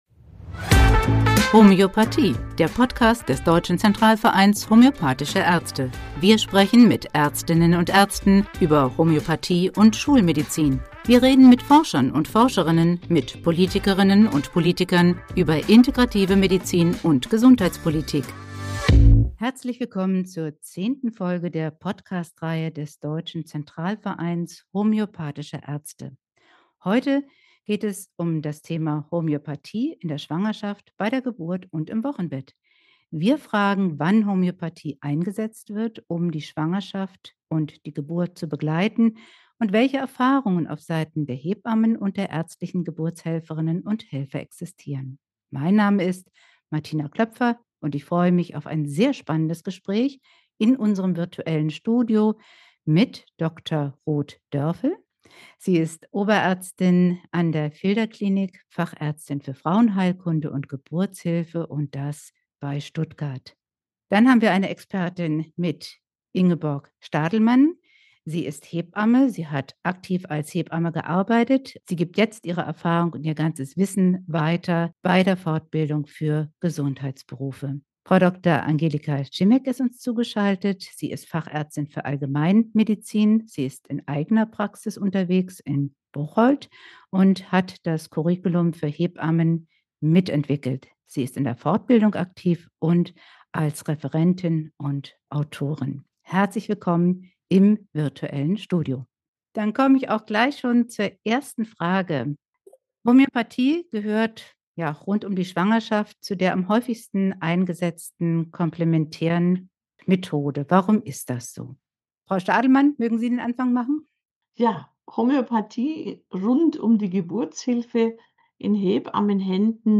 Wie kann Homöopathie ärztliche Geburtshelferinnen und Hebammen in der Praxis unterstützen? In dieser Folge sprechen zwei Ärztinnen und eine Hebamme über den Einsatz der Homöopathie in Schwangerschaft, Geburt und Wochenbett.